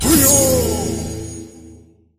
Reflect SFX
反弹音效
CR_monk_reflect_sfx_01.mp3